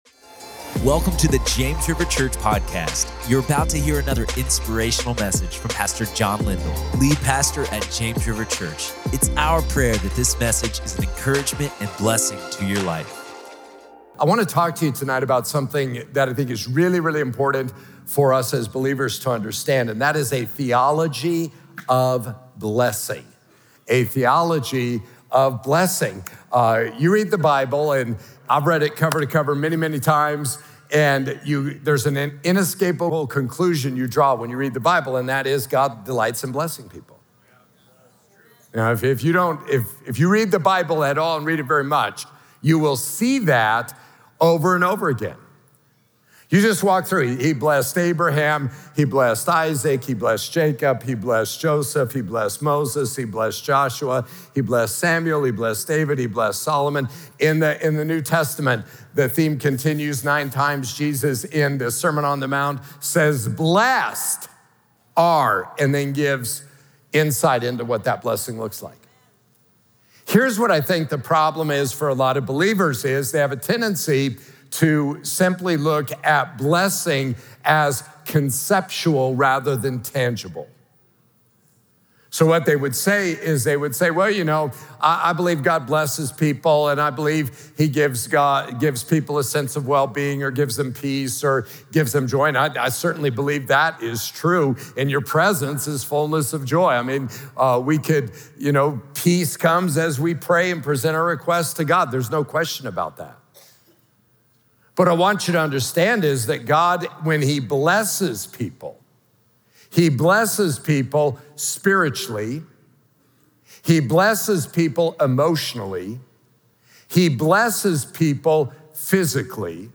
A Theology of Blessing | Prayer Meeting